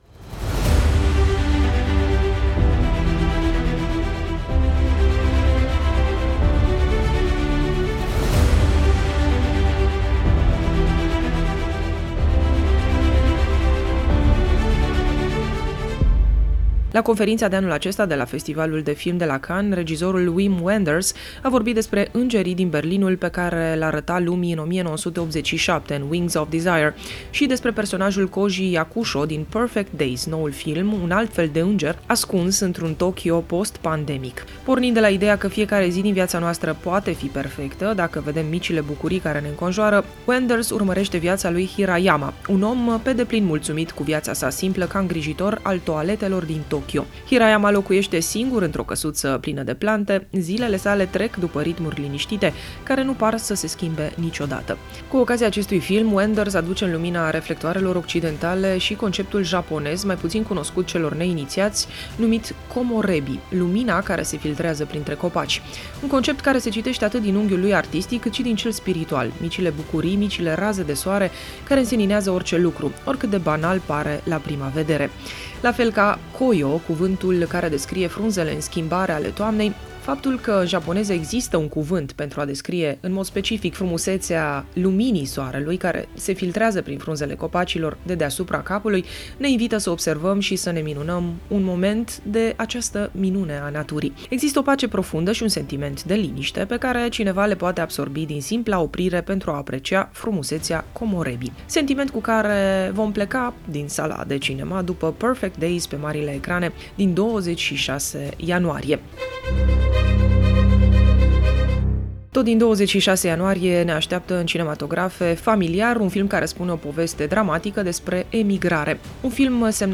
știri proaspete din lumea cinematografiei, alternate de melodii celebre